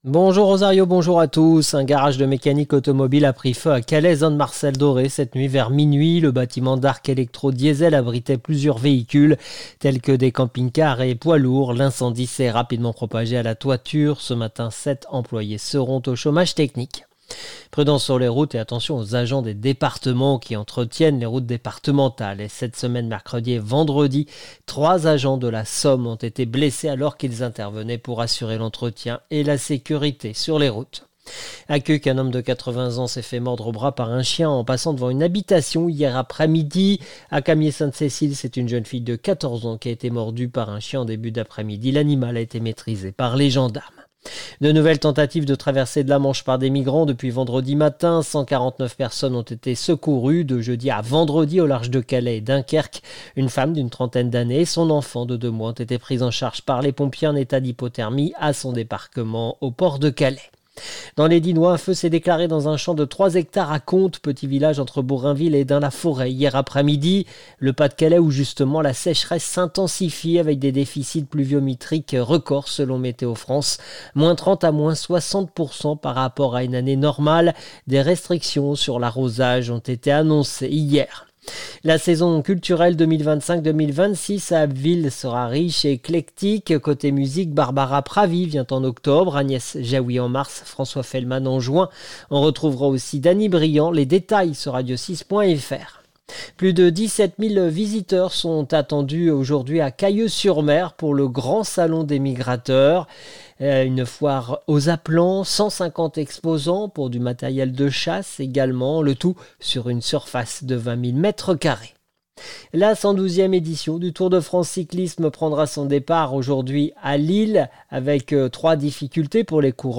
Le journal du Samedi 5 juillet 2025